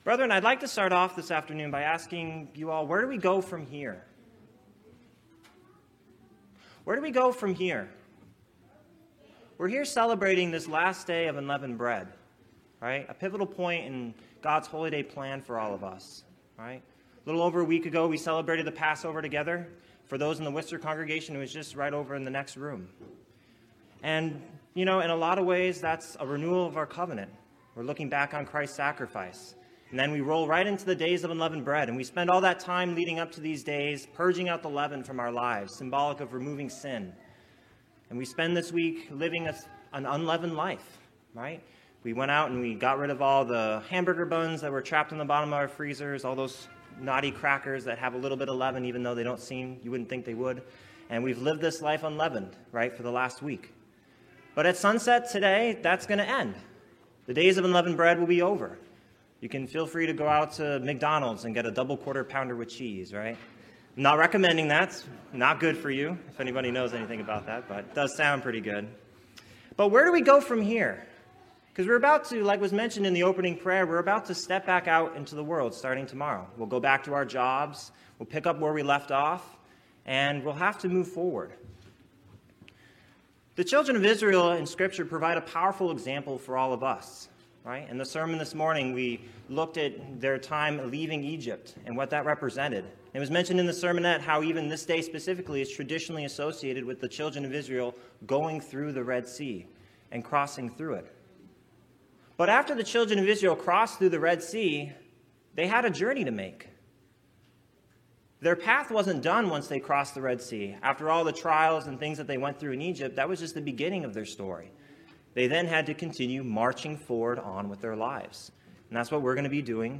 As God led Israel out of Egypt, Israel was met with a series of situations where they had an opportunity to grow in faith in God, relying on His power for salvation. Reading through a few events after leaving Egypt, this sermon examines both the good and bad responses from Israel and the lessons we can learn from their behavior.